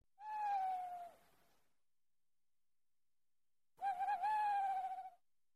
Другие рингтоны по запросу: | Теги: филин, owl, Сова
Категория: Различные звуковые реалтоны